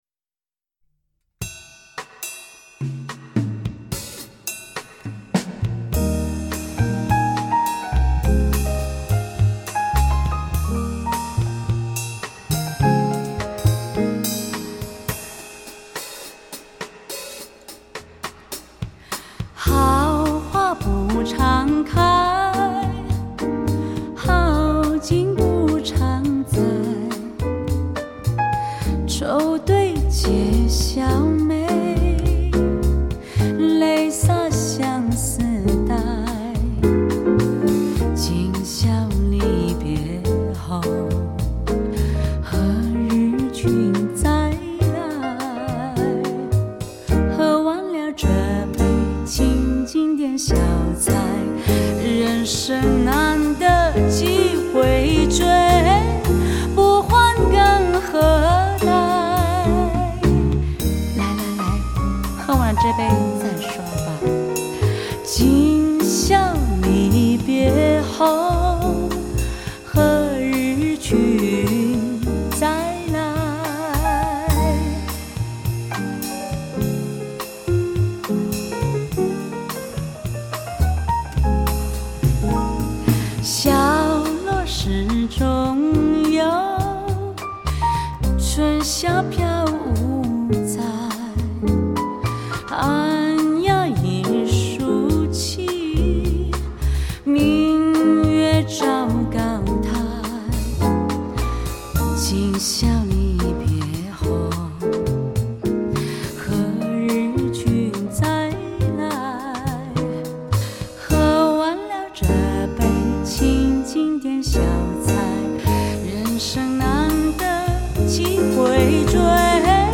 今次演绎老歌时加入少许爵士风格
她的歌声甜润，加上伴奏乐器的音色通透无匹，令此碟听到人不忍叫停。